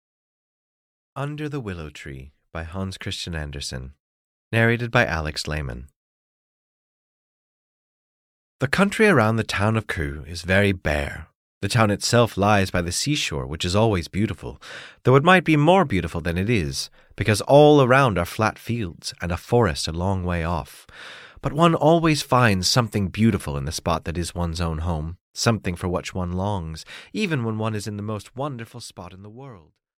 Under the Willow Tree (EN) audiokniha
Ukázka z knihy